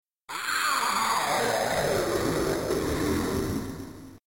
She tries to say something to you as well, which sounds like "Help me..."